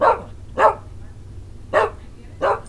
barks